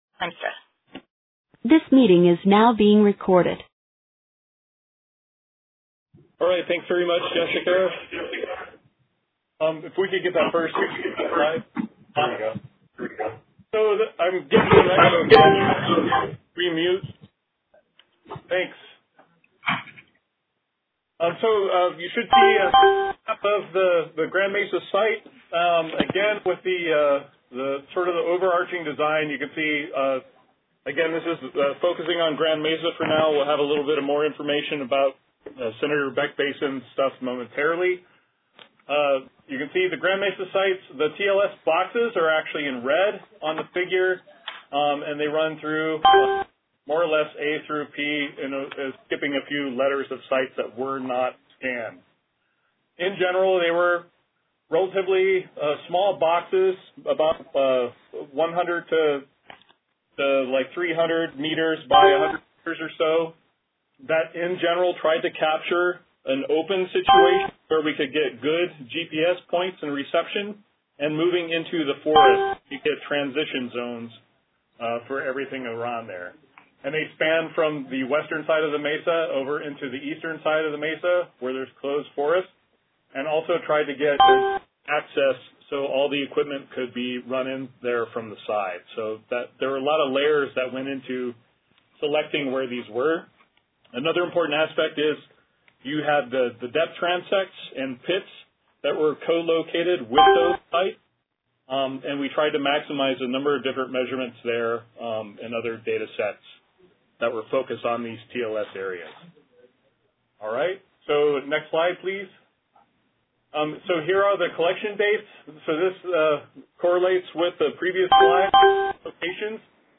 NASA SnowEx Town Hall, AGU Fall Meeting, December 13, 2018:
Voice recording